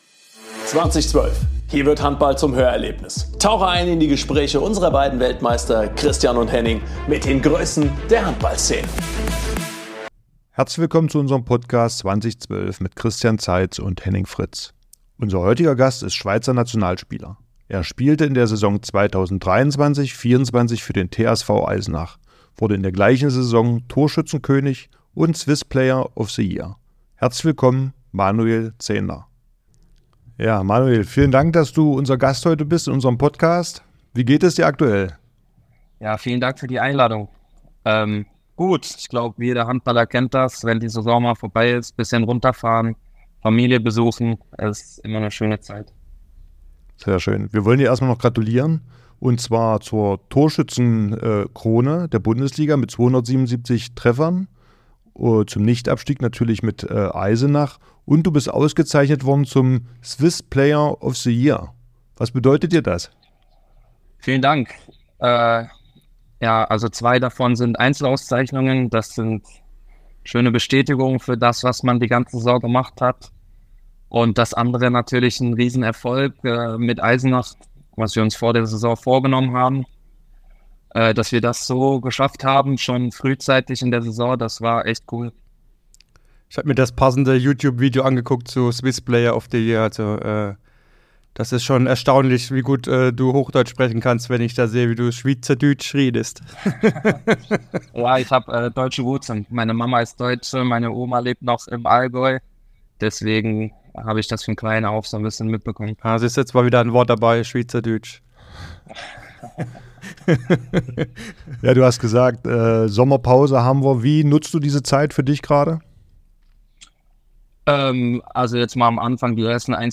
Willkommen zur 3. Staffel des Podcasts "20/12" mit den Gastgebern Christian Zeitz und Henning Fritz!
Die Gastgeber sprechen mit Manuel über seine Entwicklung im Verein, die vergangene Saison in Eisenach, seinen Trainer, seine Ziele und vieles mehr.